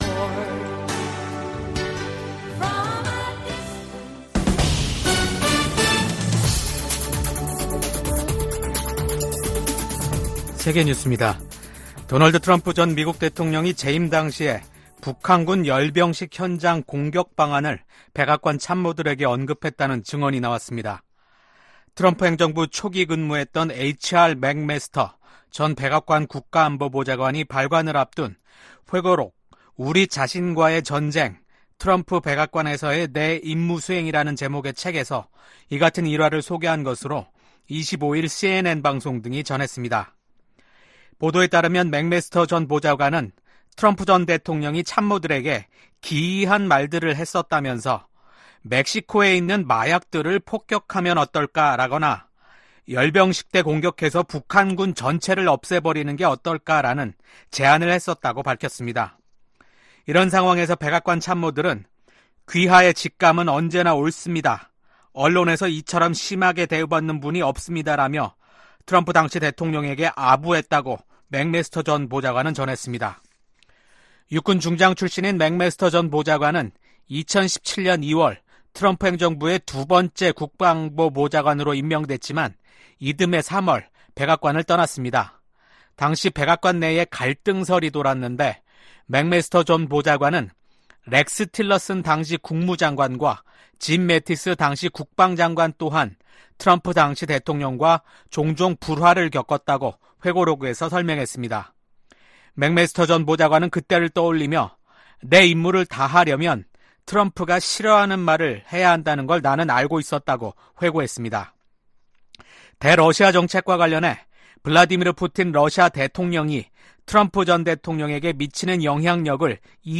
VOA 한국어 아침 뉴스 프로그램 '워싱턴 뉴스 광장' 2024년 8월 27일 방송입니다. 미국 백악관 국가안보보좌관이 중국을 방문해 북한 문제 등 미중 현안을 논의합니다. 북한이 김정은 국무위원장의 현지 지도 아래 자신들이 만든 자폭형 무인공격기를 처음 공개했습니다.